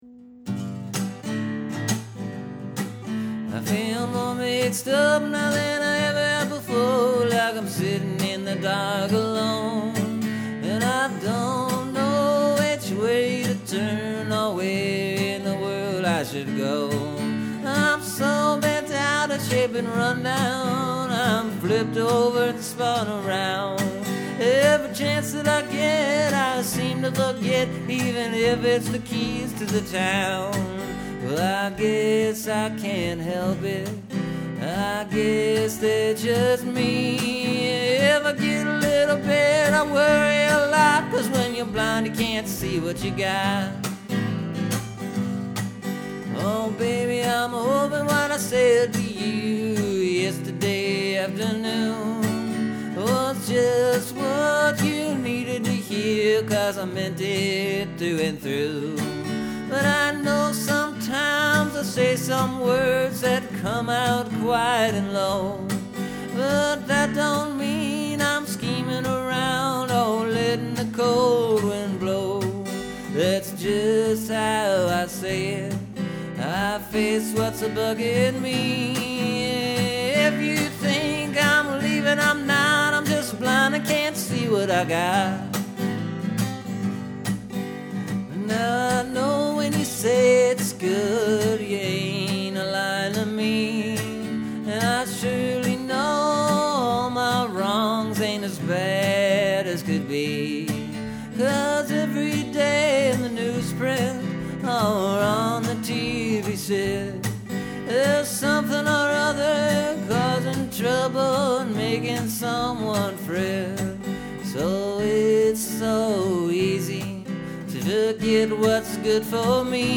Lots of words, lots of chords.
It’s all right out there. Nothing’s hiding, nothing’s waiting to pop out at the last second.